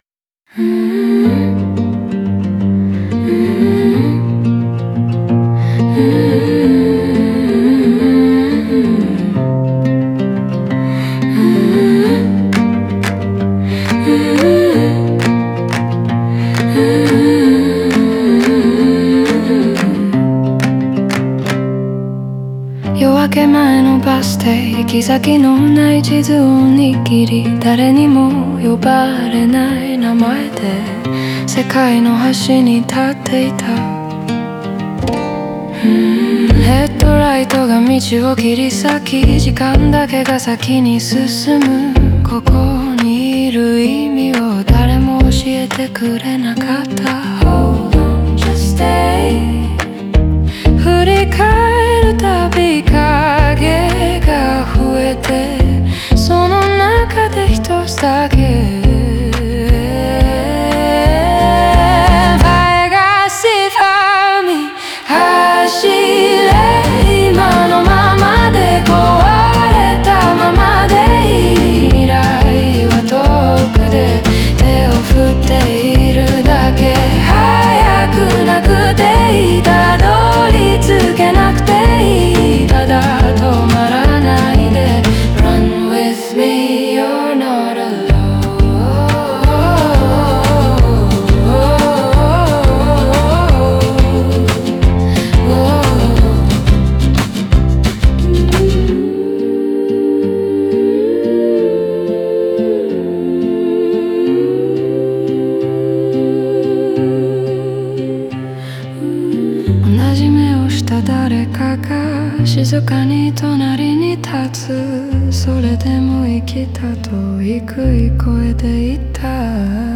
前半では行き先の見えない日常や、自分の存在価値を見失った感覚が静かに描かれ、音楽的にも抑制された語り口が続きます。
サビに向かうにつれ、楽曲は徐々に広がりを持ち、感情も内省から肯定へと変化します。